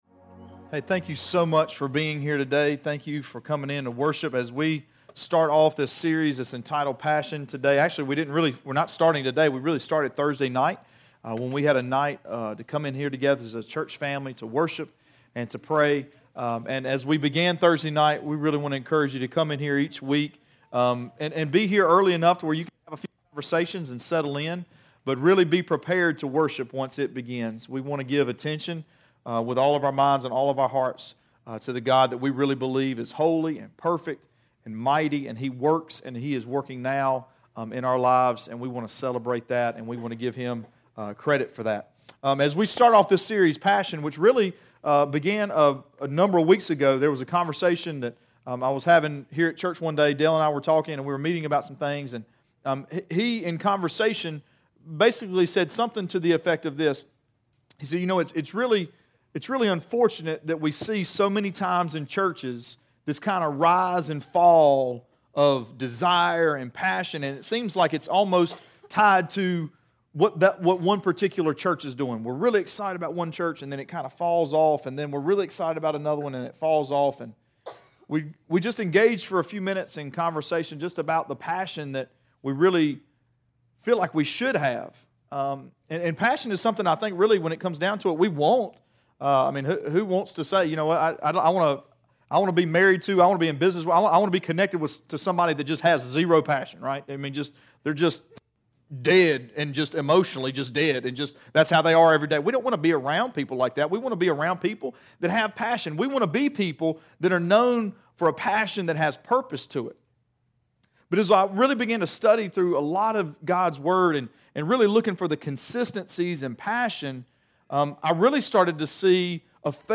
Sermons Archive - Page 36 of 60 - REEDY FORK COMMUNITY CHURCH